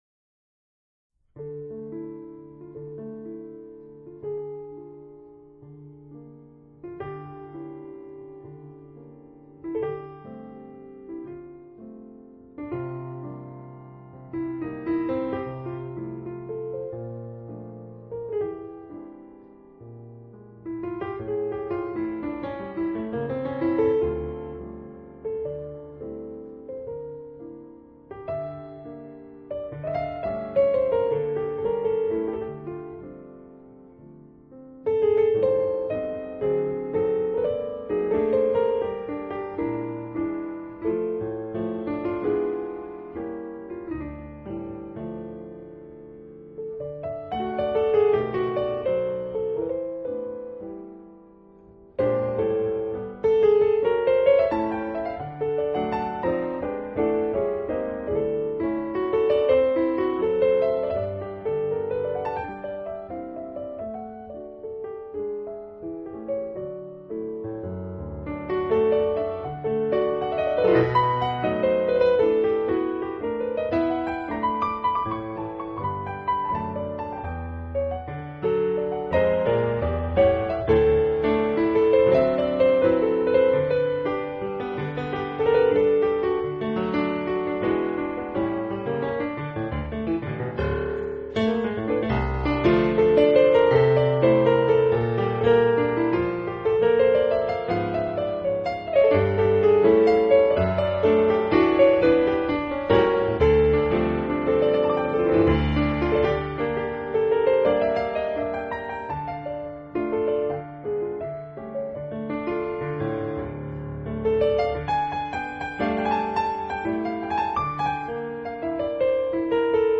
琴键在其抚弄下音色由暗渐 而转亮，诗篇的乐音似涓涓细流般地沁入，如同曲名般的如梦似幻